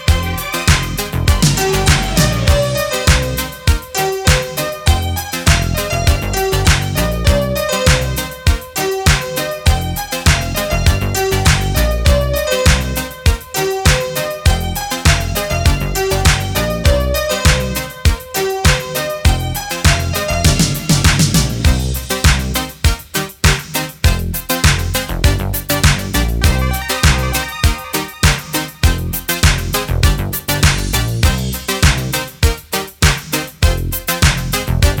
French Pop
Жанр: Поп музыка